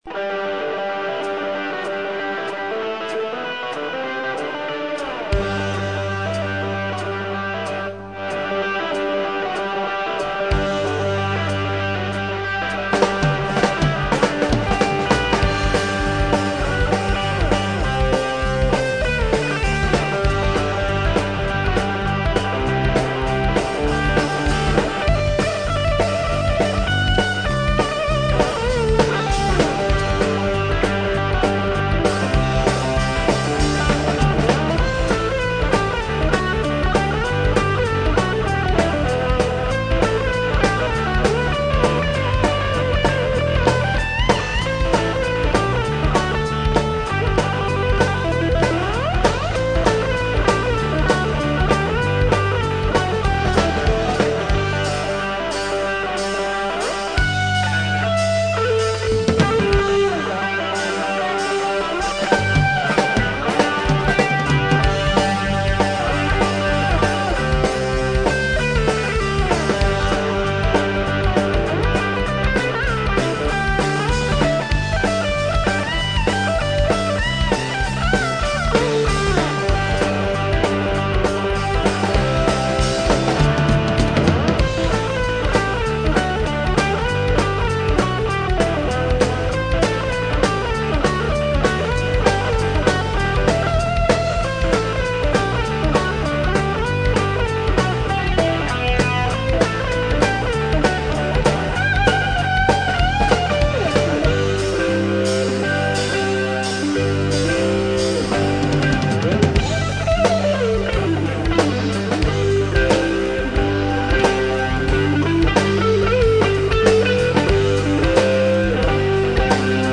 ROCK 'N ROLL